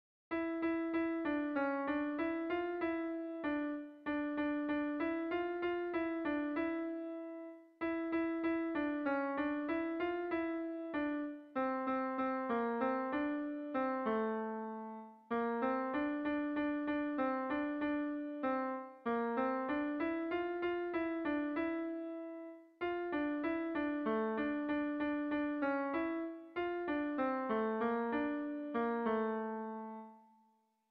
Sentimenduzkoa
Dima < Arratia-Nerbioi < Bizkaia < Euskal Herria
Zortziko handia (hg) / Lau puntuko handia (ip)
A1A2BD